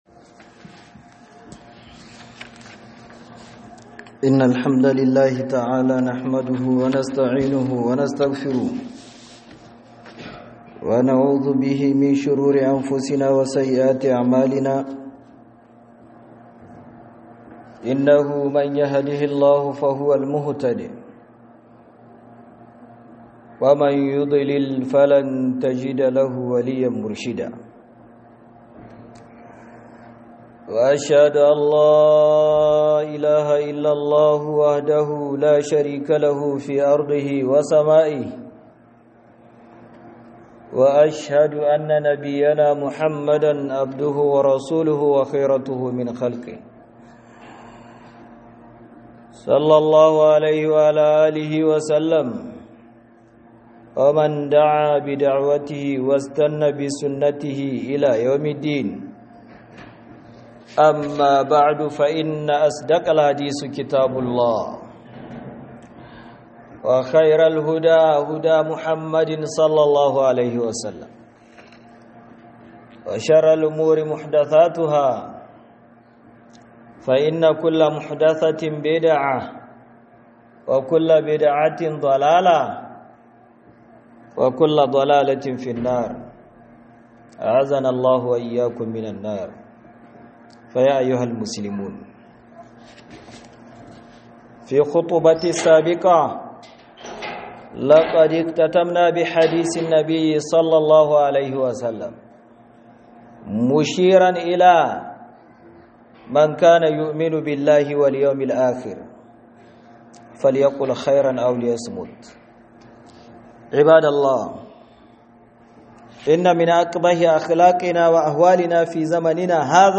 2026-04-03_12'43'22' KA GAYI MAGANA ME KYEW - HUDUBA